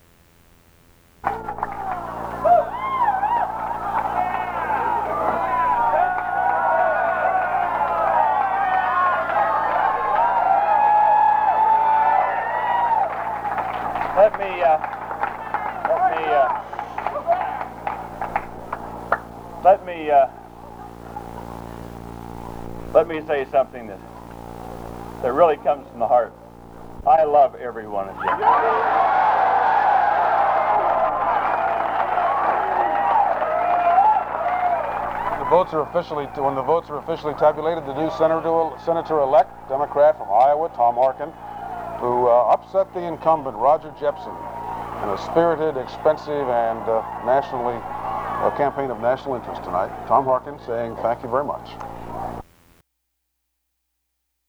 Democratic senator from Iowa, Tom Harkin, makes a victory speech after his defeat of the Republican incumbent, Senator Roger William Jepsen